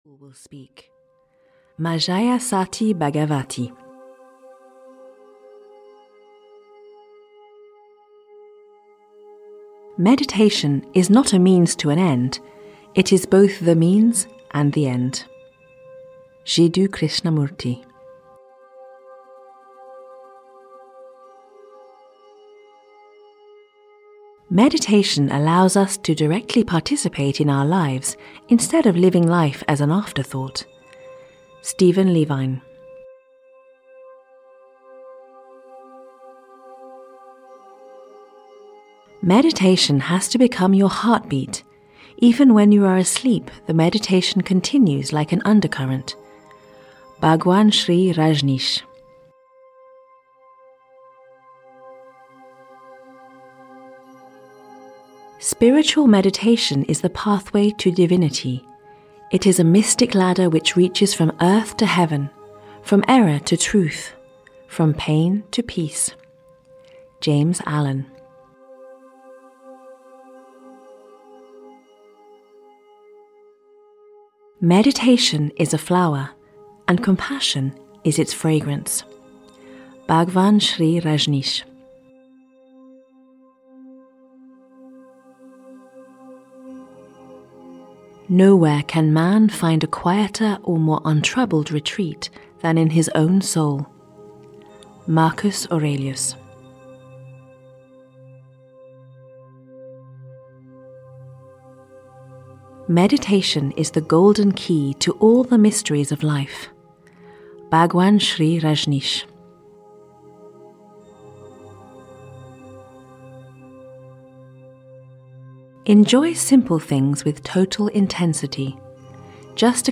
200 Quotes for Meditation (EN) audiokniha
Ukázka z knihy